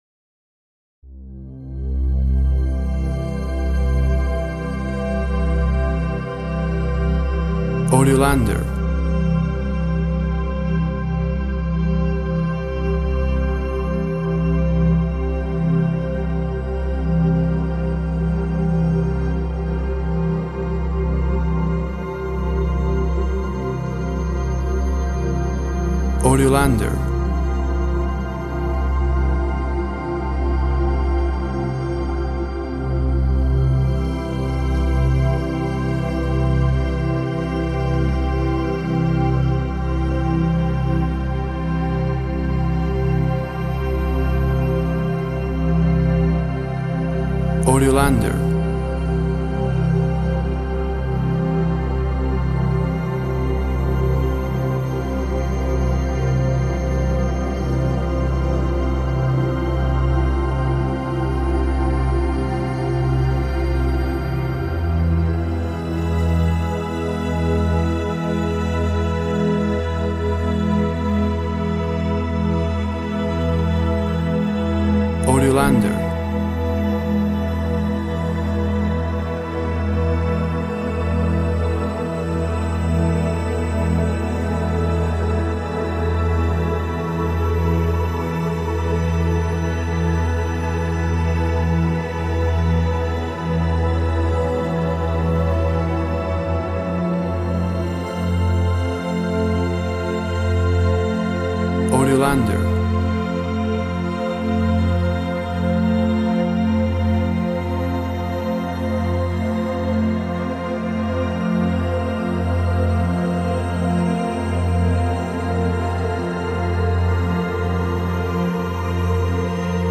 Ambient textures and pads creating a spaced out aura.
Tempo (BPM) 120